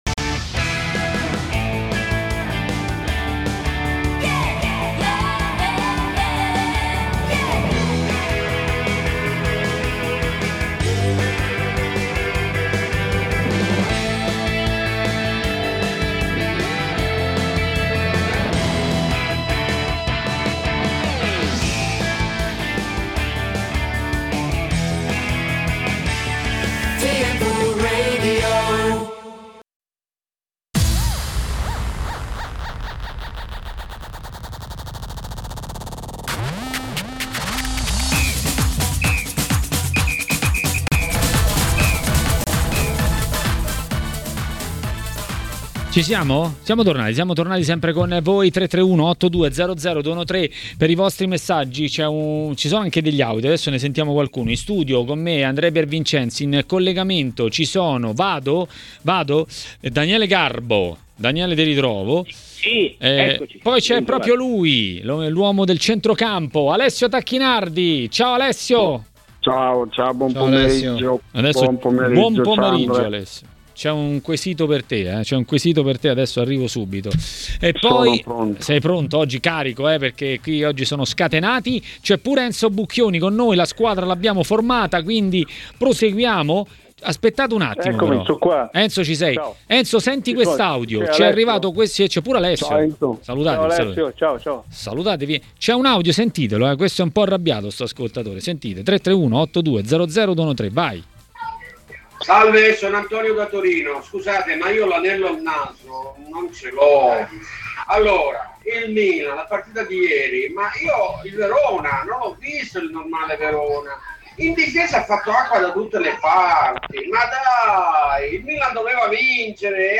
Alessio Tacchinardi, allenatore ed ex calciatore, ha parlato a Maracanà, nel pomeriggio di TMW Radio, dei temi del giorno.